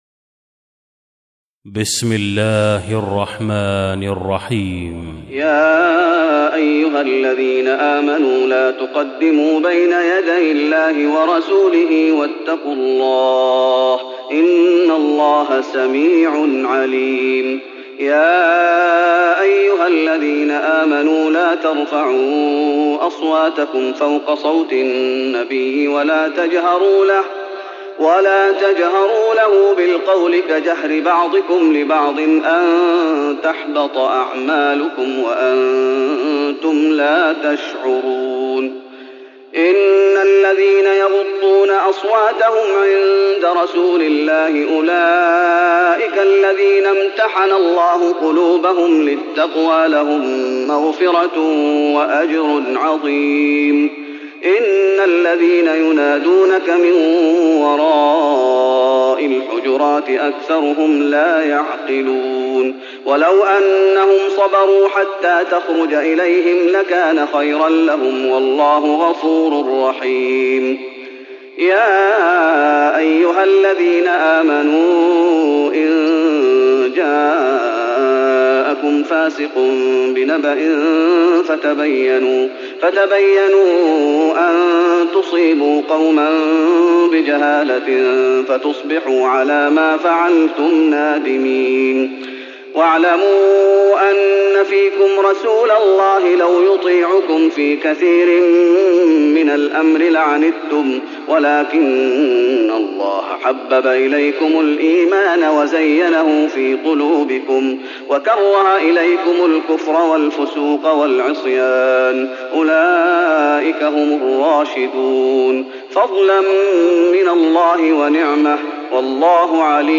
تراويح رمضان 1415هـ من سورة الحجرات Taraweeh Ramadan 1415H from Surah Al-Hujuraat > تراويح الشيخ محمد أيوب بالنبوي 1415 🕌 > التراويح - تلاوات الحرمين